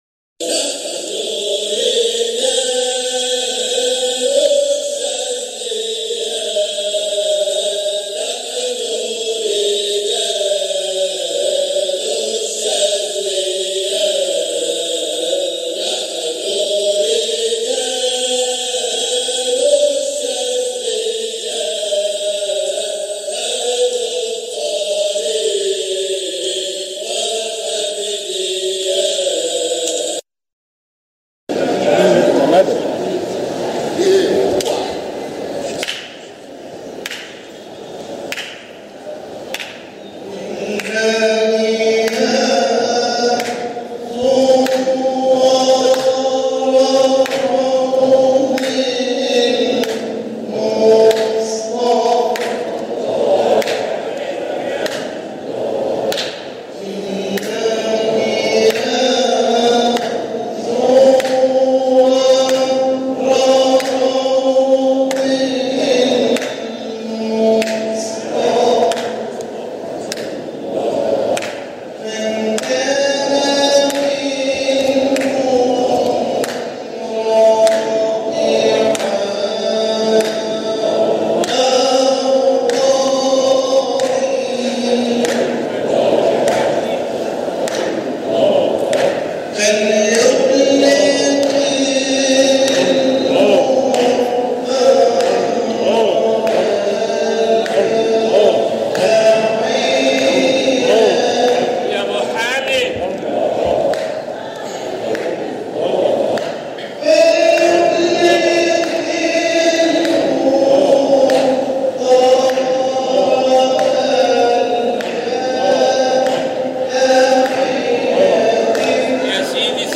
مقاطع من احتفالات ابناء الطريقة الحامدية الشاذلية بمناسباتهم
جزء من حلقة ذكر بمسجد سيدنا احمد البدوى قُدس سره رمضان 1440ه